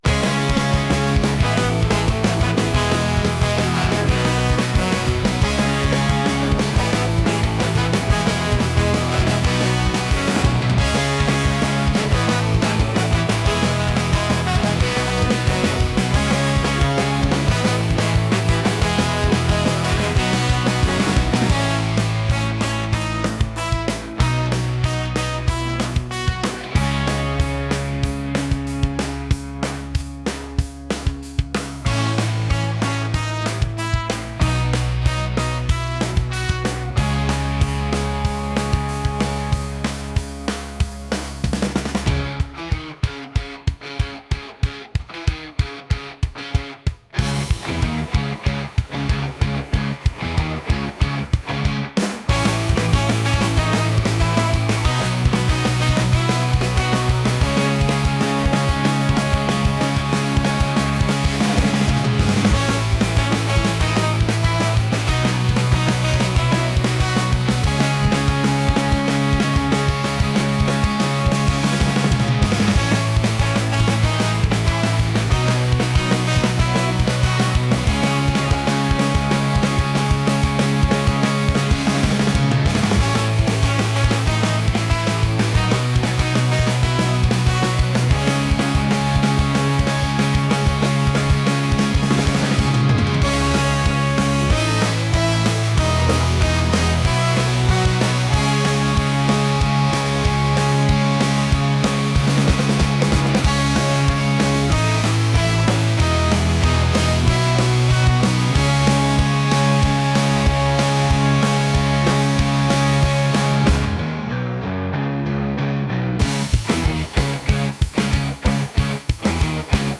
ska | punk